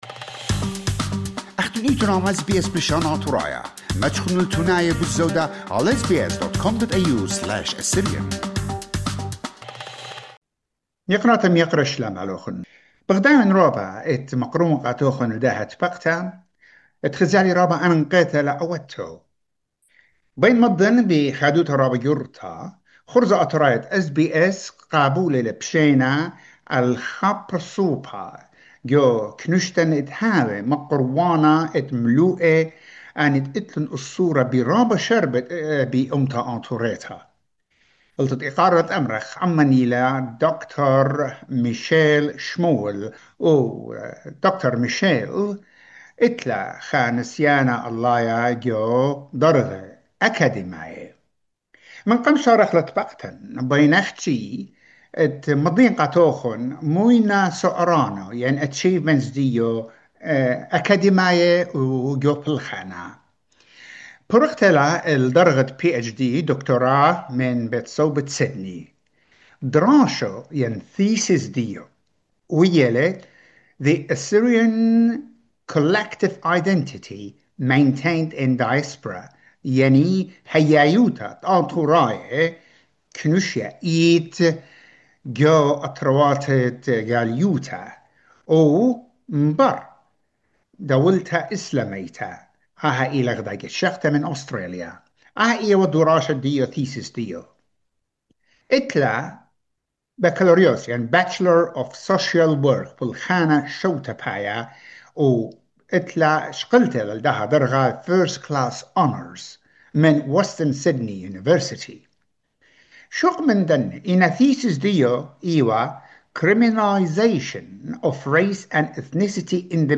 In this interview, we will take a closer look at her academic background, research and fieldwork experience, and the insights she has gained through her professional work. She will also share her broader perspective on the social, cultural, and academic dynamics shaping the Assyrian community in Australia today.